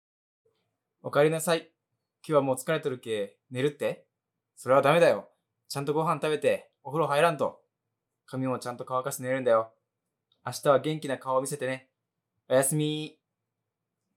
マサヒロ おやすみボイス
マサヒロおやすみボイス.wav